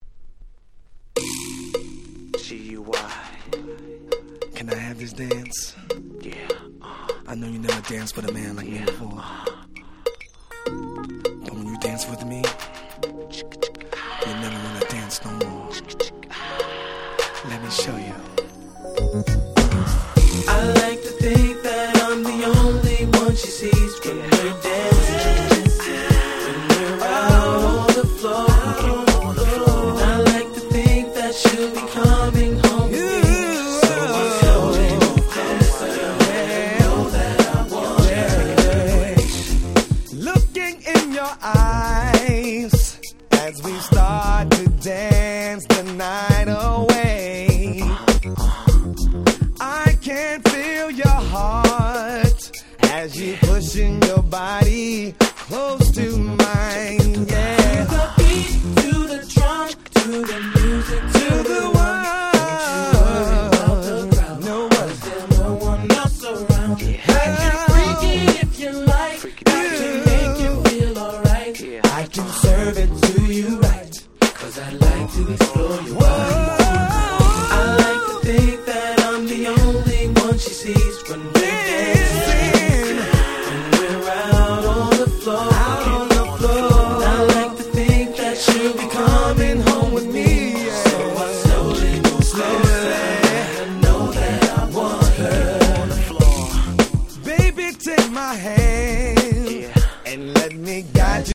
99' Super Hit R&B !!
NJS ニュージャックスィング ハネ系 90's